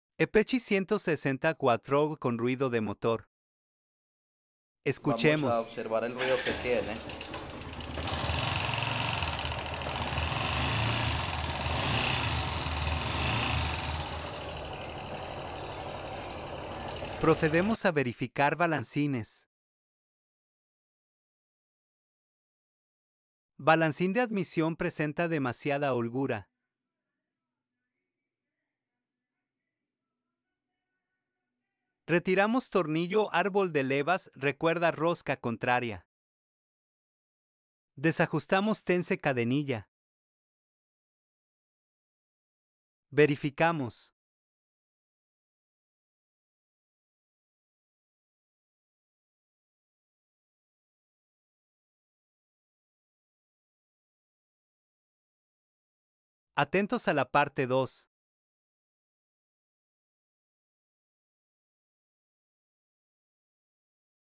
Apache 4v 160 Con Ruido Sound Effects Free Download